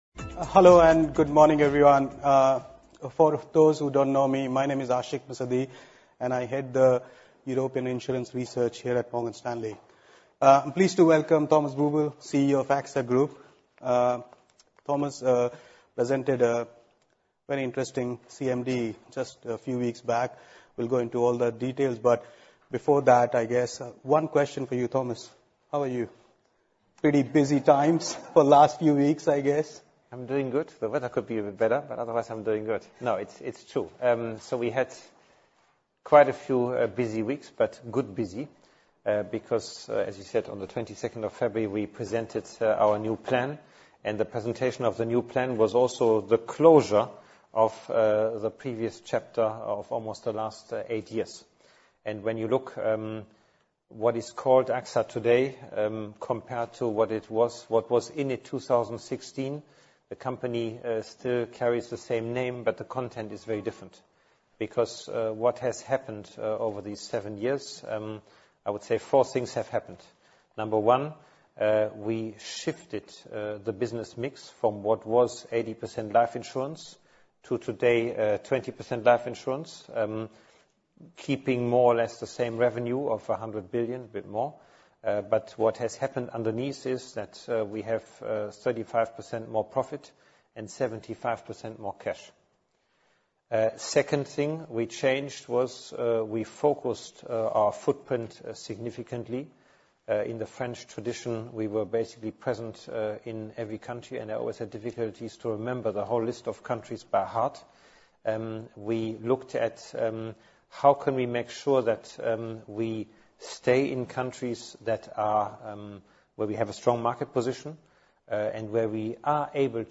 will be attending the Morgan Stanley European Financials Conference 2024 in London.